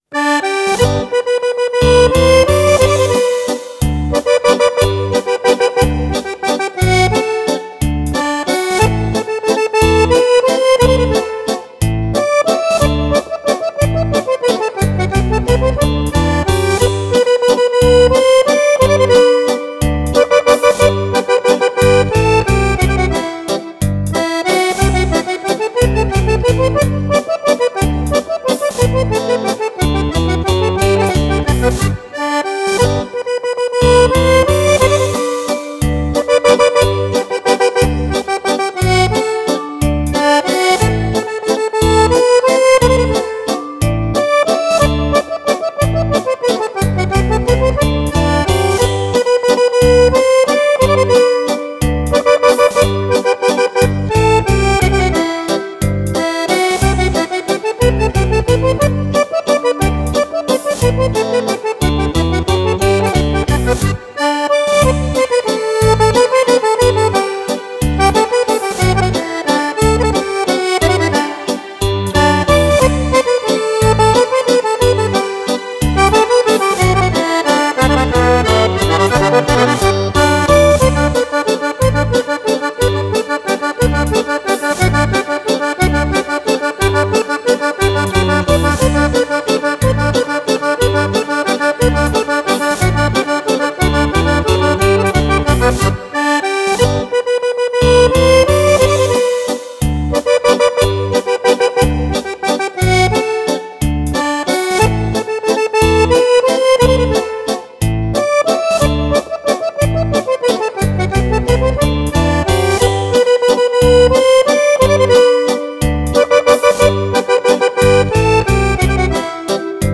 Valzer
Valzer per Fisarmonica